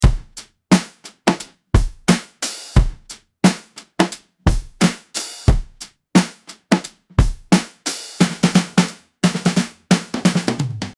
Vintage 1960s Drum Groove Loop 88 BPM
Description: Vintage 1960s drum groove loop 88 BPM. Loopable acoustic drum beat inspired by 1960s Ludwig kits.
Genres: Drum Loops
Tempo: 88 bpm